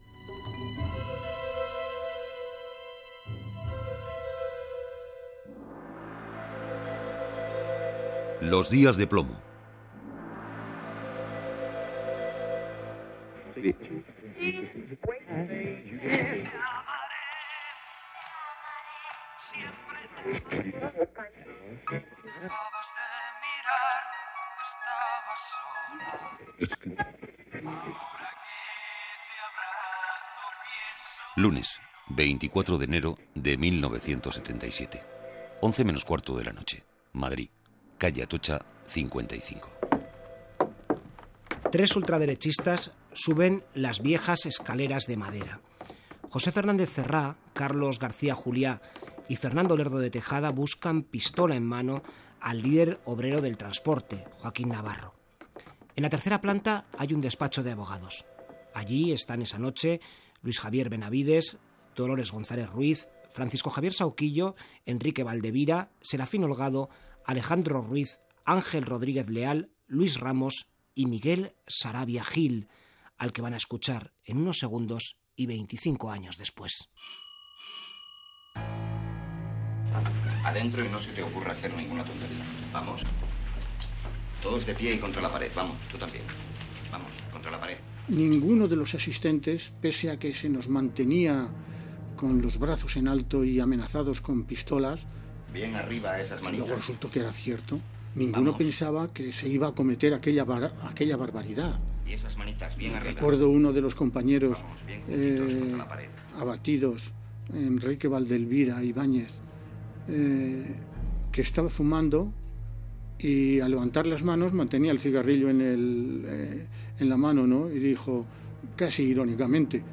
Reportatge emès 25 anys després dels assassinats dels advocats laboralistes d'Atocha, Madrid, el 24 de gener de 1977. Parlen els supervivients